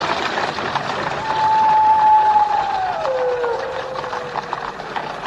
• wolves howling.ogg
[wolves-howling-sound-effect]_z8c.wav